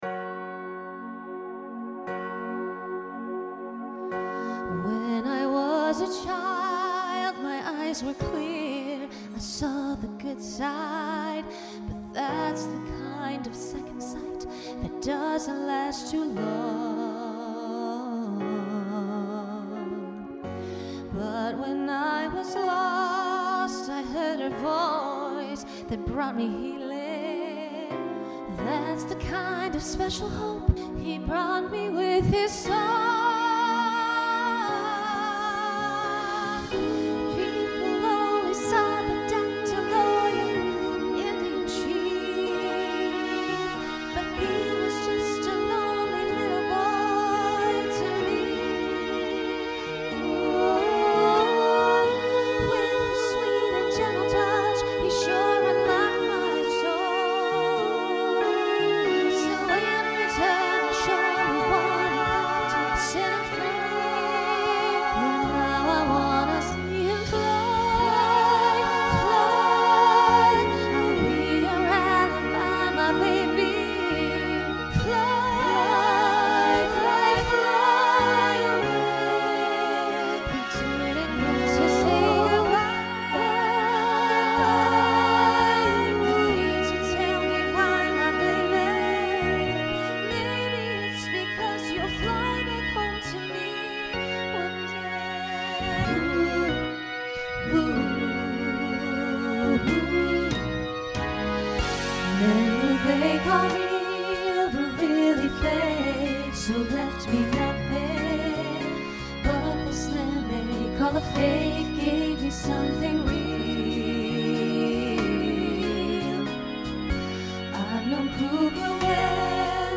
Voicing SSA Instrumental combo Genre Broadway/Film
Ballad